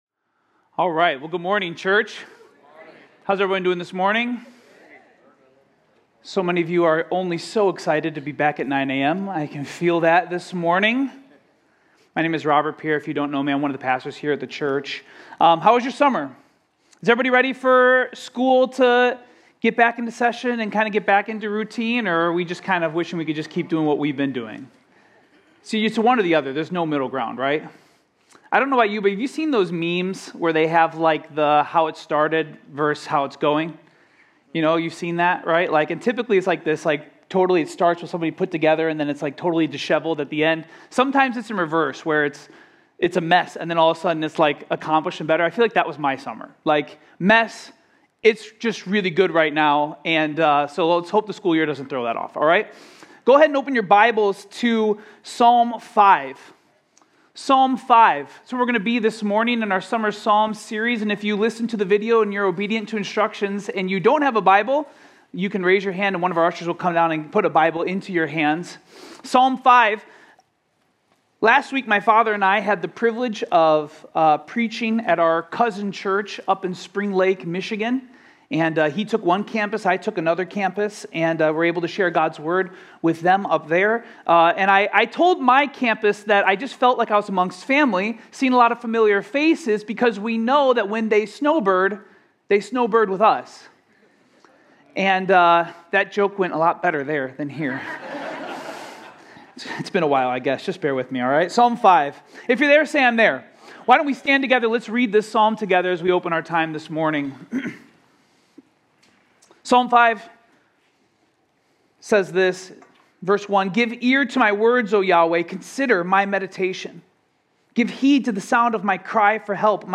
Home Sermons Summer Psalms 2025